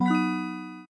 锻造成功.mp3